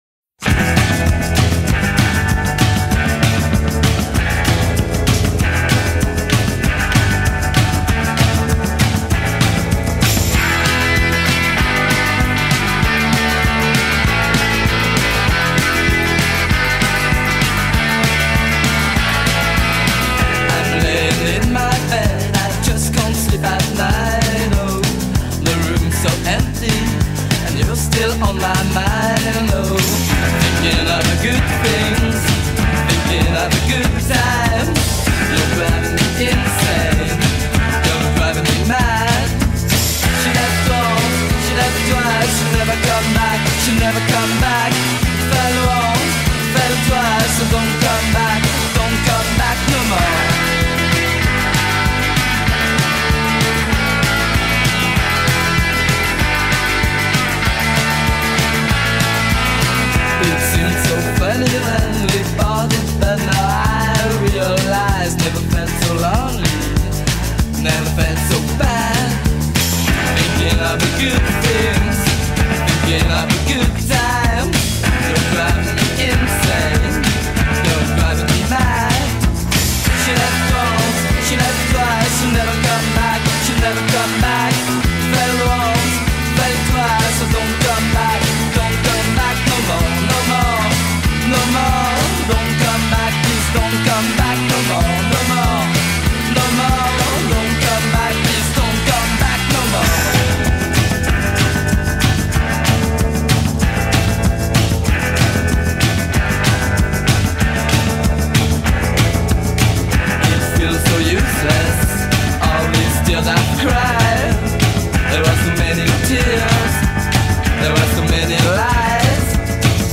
punky new wave band